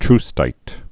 (trstīt)